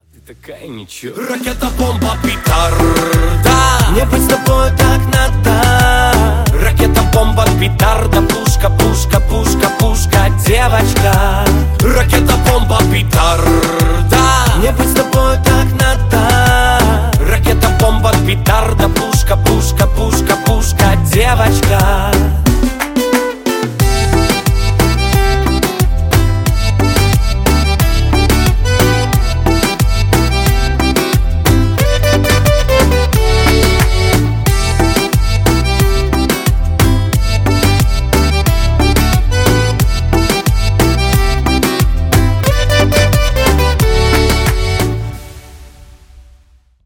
• Качество: 128, Stereo
поп
гитара
позитивные
мужской вокал
веселые
заводные
испанская гитара